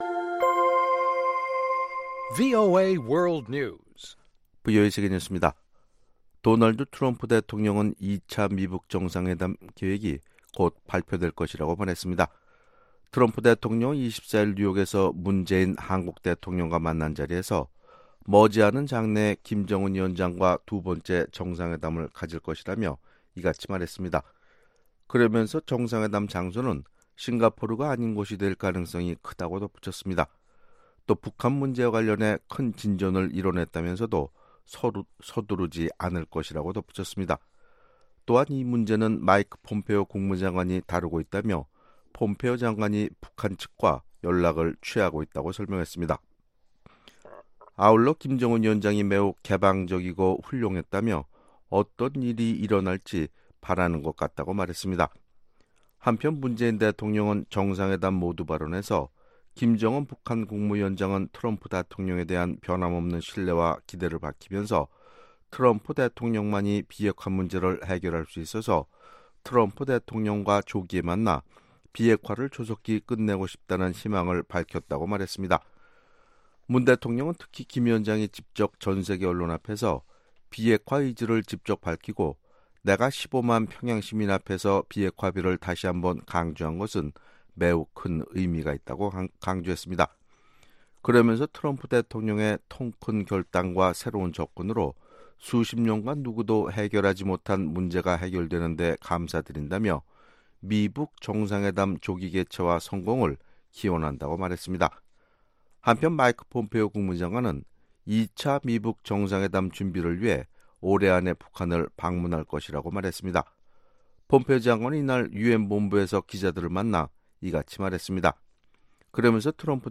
VOA 한국어 아침 뉴스 프로그램 '워싱턴 뉴스 광장' 2018년 9월 25일방송입니다. 마이크 폼페오 국무장관은 북한과 비핵화 달성을 위한 특정시설과 무기에 대한 논의가 진행 중이라고 밝혔습니다. 북한에서 핵사찰이 이뤄진다면 일부가 아닌 모든 시설을 대상으로 해야 한다고 니키 헤일리 유엔주재 미국 대사가 밝혔습니다.